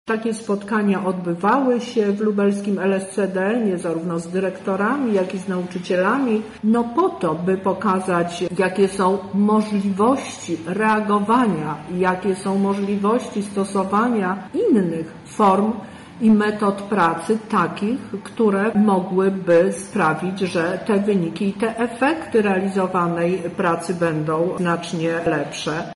To nie są gołe dane. Wspólnie ze szkołami co roku wyciągamy z tego wnioski na przyszłość – mówi Teresa Misiuk – Lubelski Kurator Oświaty